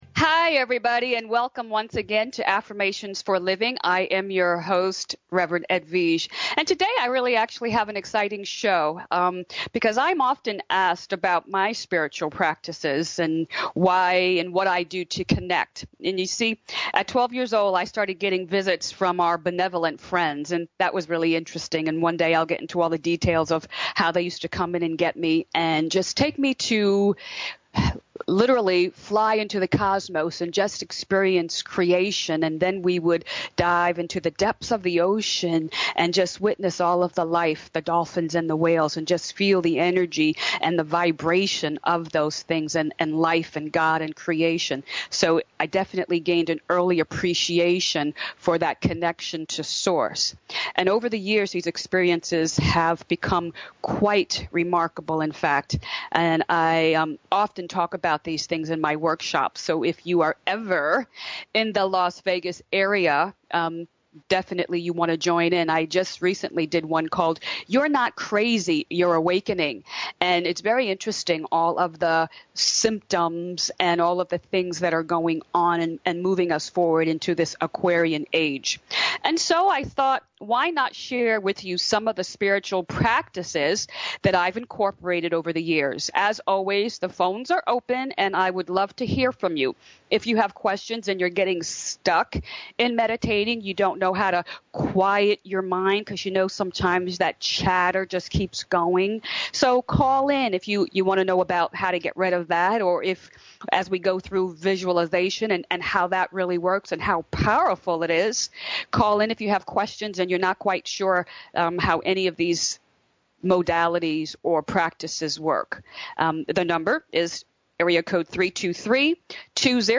Each week you’re invited to join in on our upbeat discussion as we look deeper into spiritual based principles that can change your life.